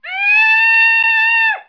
دانلود صدای حیوانات جنگلی 15 از ساعد نیوز با لینک مستقیم و کیفیت بالا
جلوه های صوتی